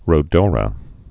(rō-dôrə)